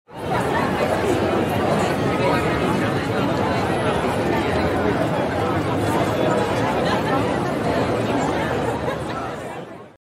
Короткий фоновый звук с разговором людей